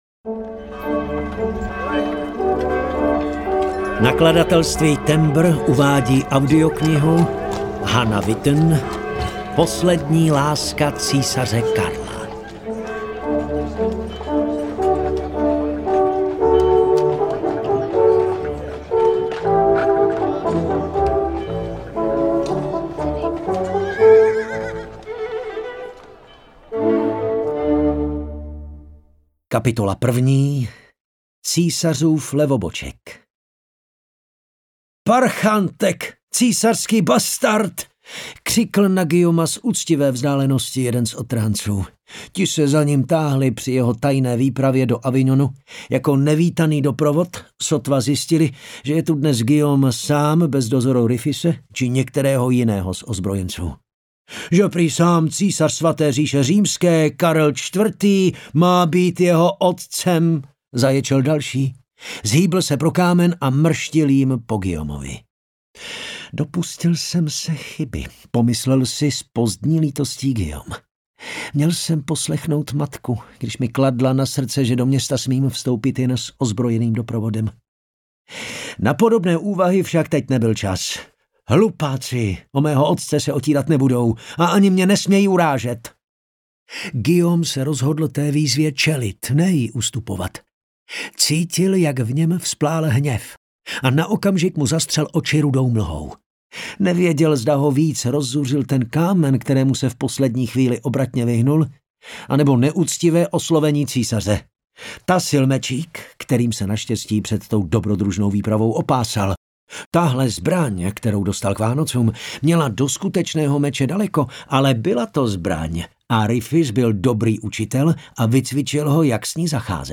Audio knihaPoslední láska císaře Karla - 2. vydání
Ukázka z knihy